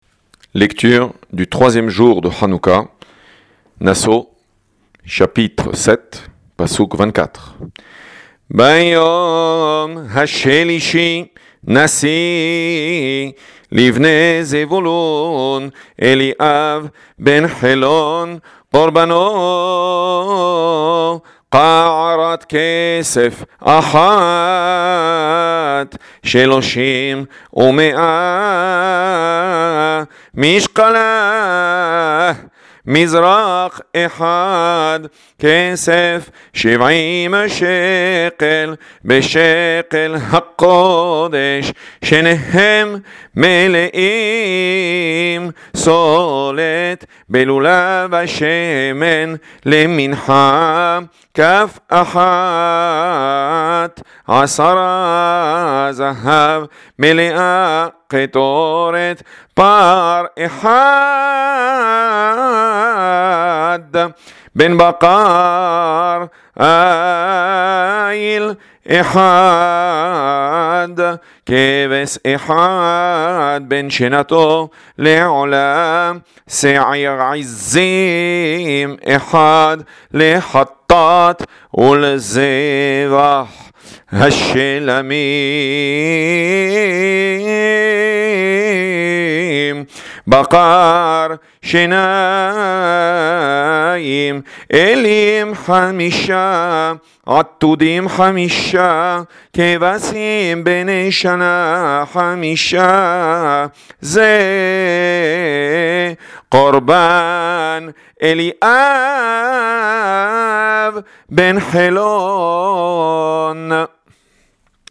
Hanoukah - Lecture du 3ème jour de H’anouka